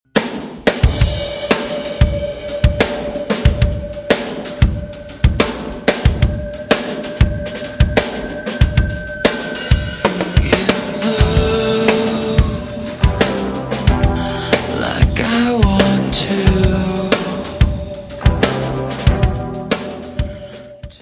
voice/guitar
drums
Turntables
Music Category/Genre:  Pop / Rock